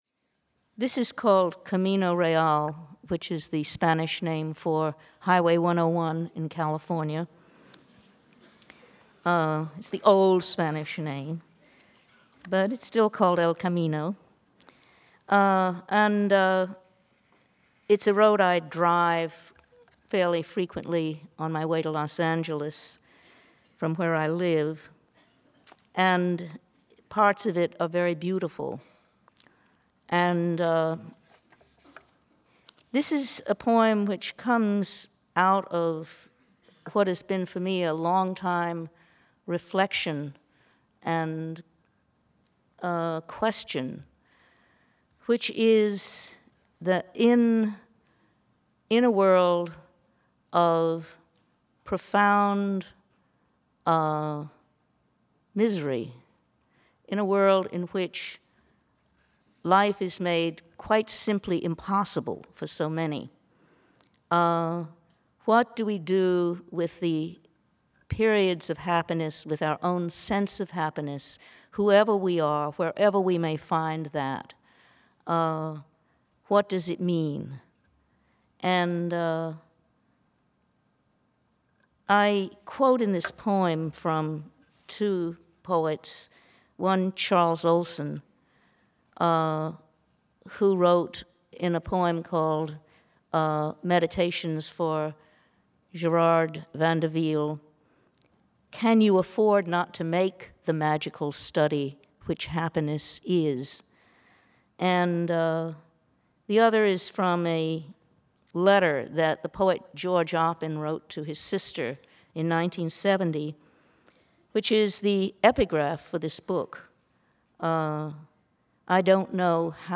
Audio files here are from Rich’s recordings for the University of Cincinnati’s Elliston Project; from the 92nd Street Y in New York City; from PennSound; and from the Voice of the Poet series edited by J.D. McClatchy in 2002 for Random House Audio.
(92Y in 1999)